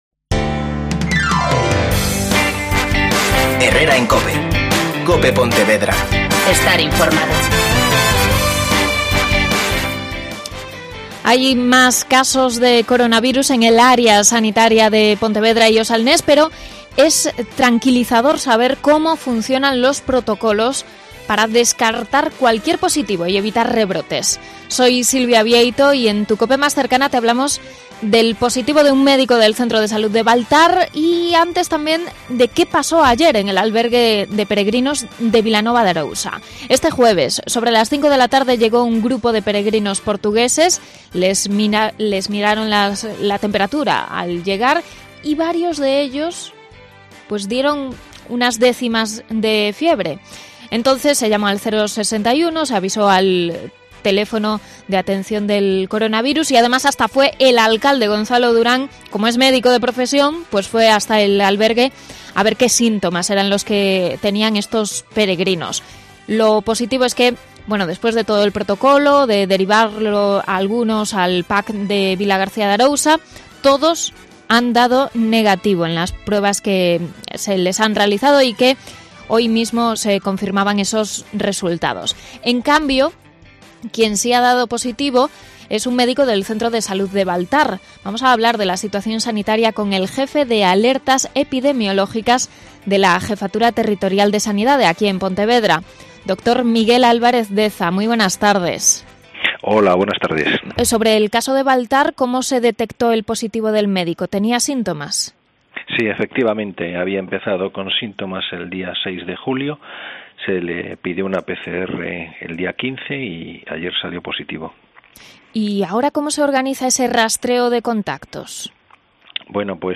Pontevedra